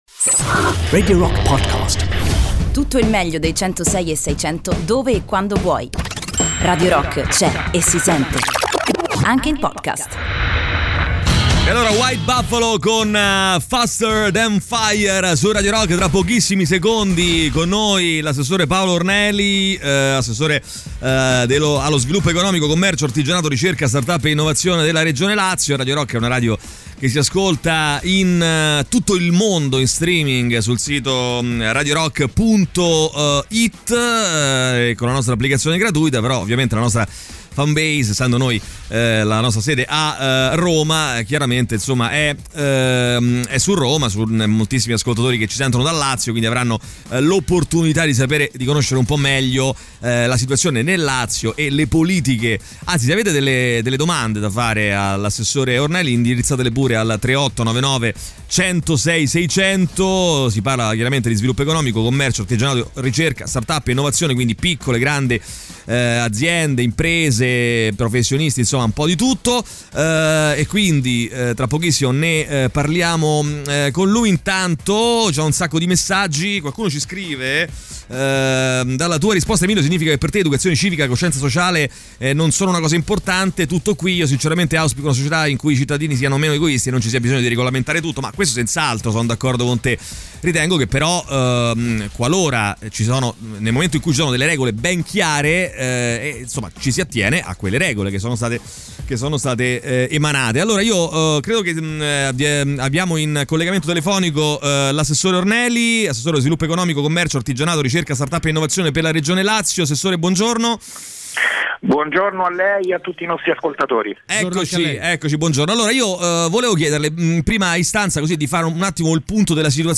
Intervista: "Paolo Orneli" (15-04-20)
in collegamento telefonico con Paolo Orneli, assessore regionale allo sviluppo economico, Commercio e artigianato, Ricerca, Start-up e innovazione.